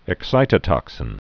(ĭk-sītə-tŏksĭn)